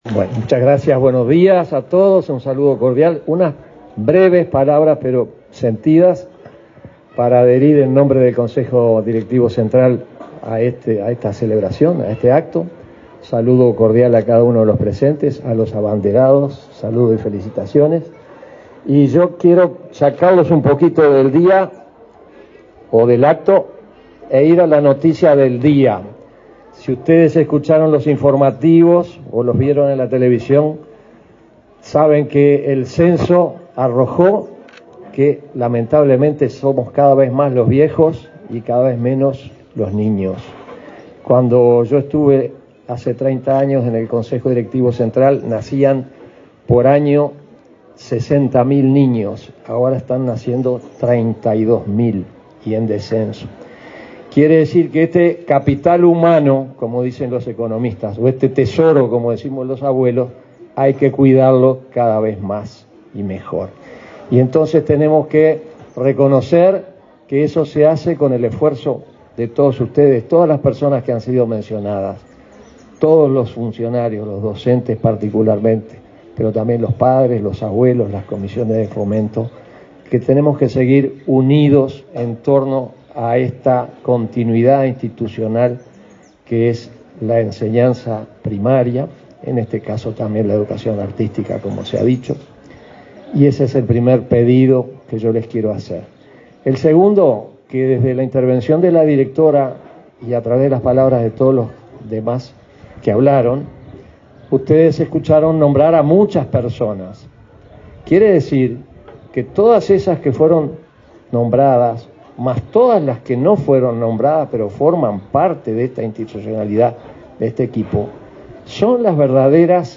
Palabras del presidente interino de la ANEP, Juan Gabito Zóboli
Este martes 28 en Canelones, el presidente interino de la ANEP, Juan Gabito Zóboli, participó de la inauguración de la escuela n.° 317 de educación